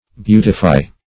beautify - definition of beautify - synonyms, pronunciation, spelling from Free Dictionary
Beautify \Beau"ti*fy\ (b[=u]"t[i^]*f[imac]), v. t. [imp. & p. p.